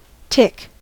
tick: Wikimedia Commons US English Pronunciations
En-us-tick.WAV